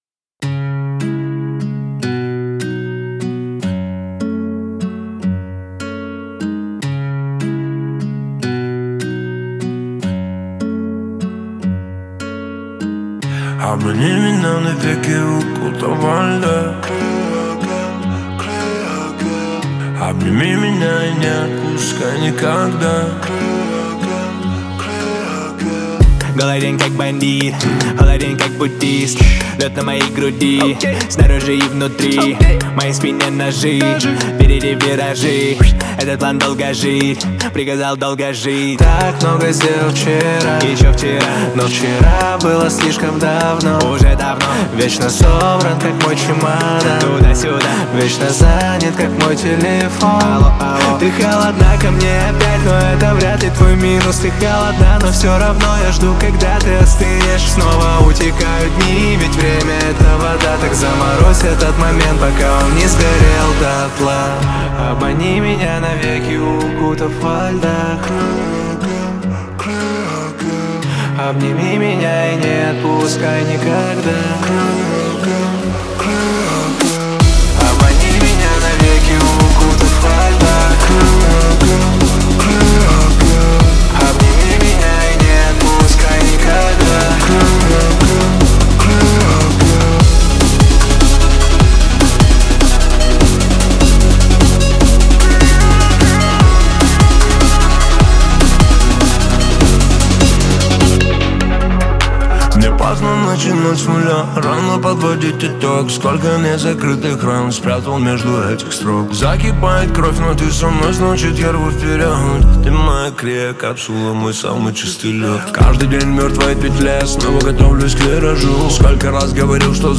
хип-хопа